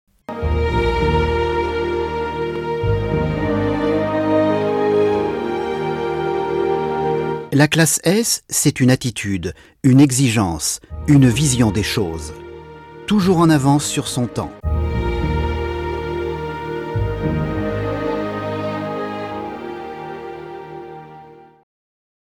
Sprecher französisch.
Kein Dialekt
Sprechprobe: Industrie (Muttersprache):
french voice over artist